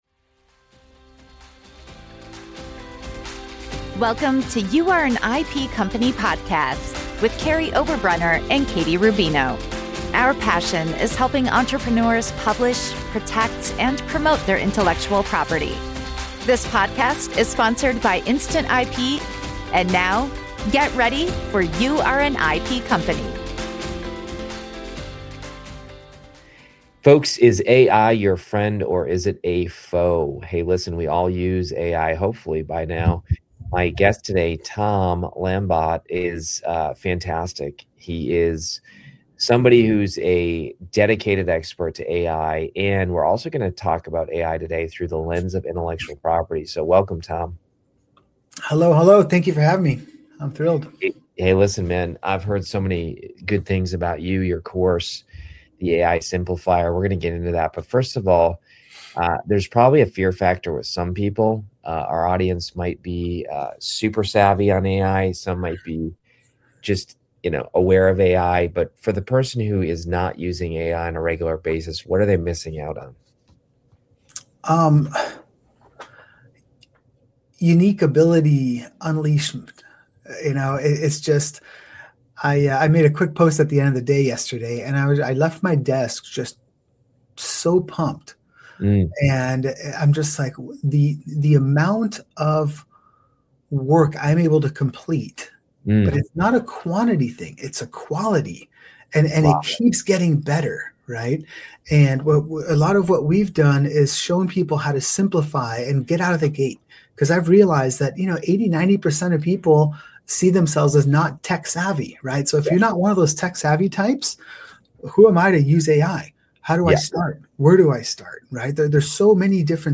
This practical conversation shows how understanding AI can unlock your unique capabilities and help you put it to work in the real world.